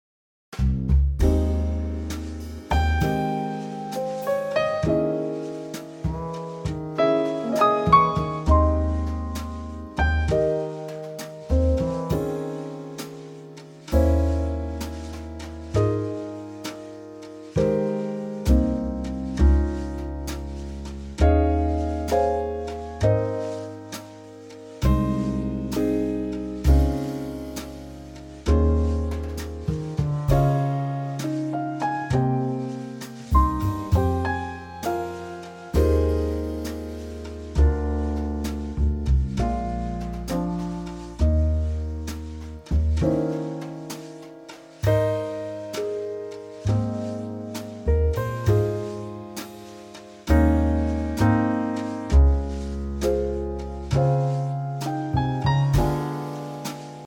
key - Ab - vocal range - Ab to Ab
Gorgeous Trio arrangement
slowed down with oodles of lovely subtle musicality added.